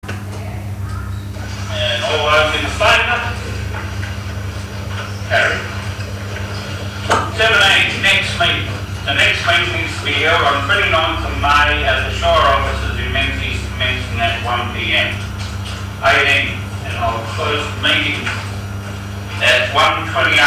Ordinary Council Meeting - 24 April 2025 » Shire of Menzies
Play Audio - Meeting Recording 1